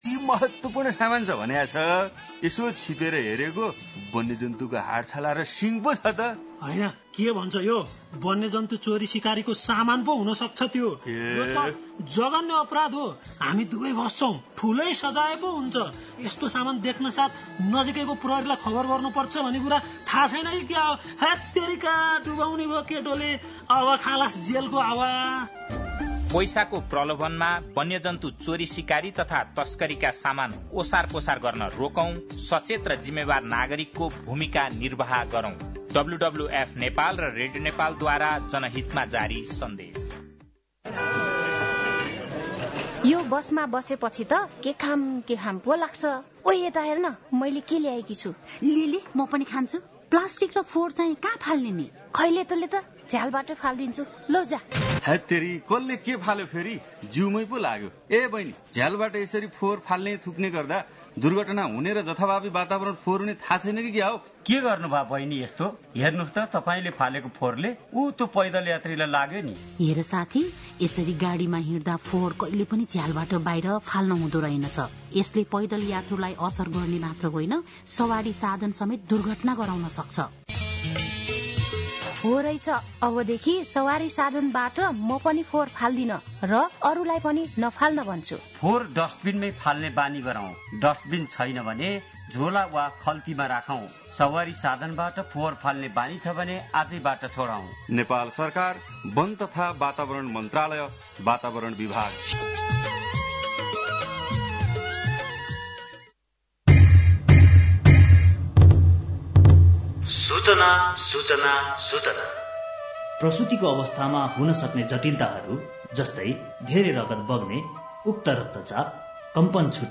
बिहान ७ बजेको नेपाली समाचार : २६ चैत , २०८१